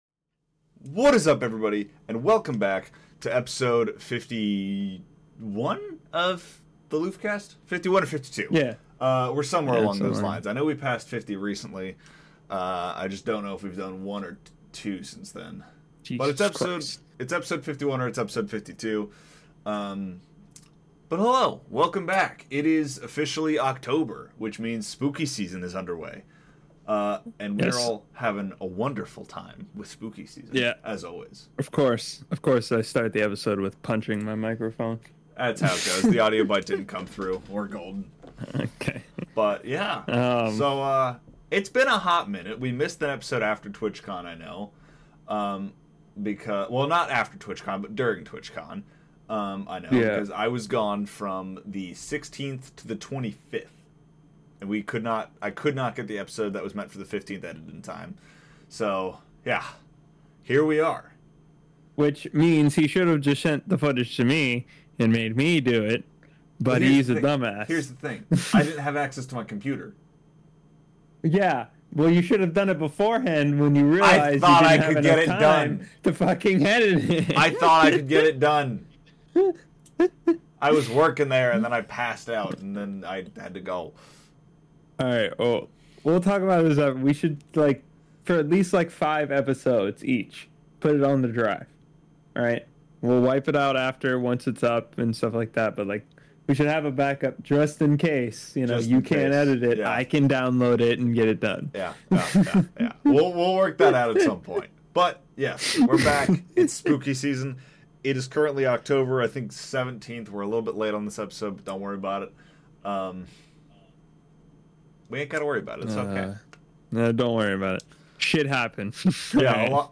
This is The LoofCast, a podcast made by two idiots talking about random internet nonsense and having fun along the way!